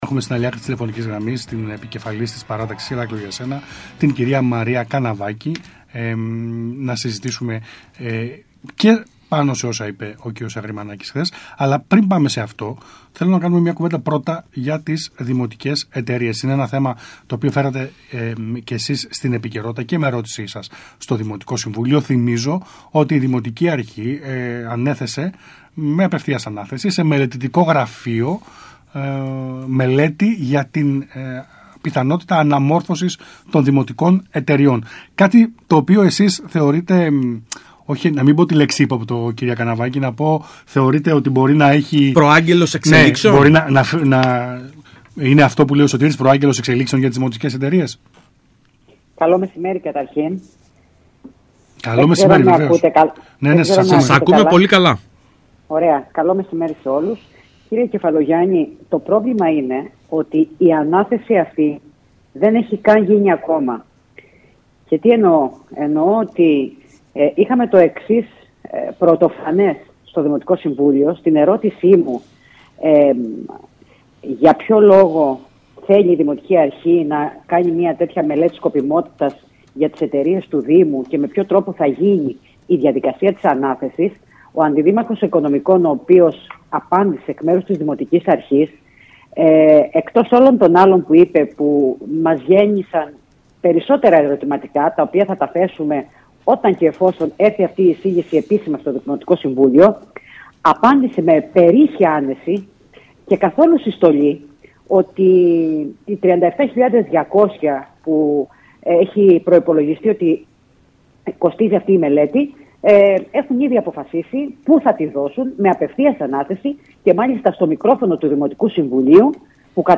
Η κ. Καναβάκη μίλησε την Παρασκευή 17 Οκτωβρίου στον ΣΚΑΙ Κρήτης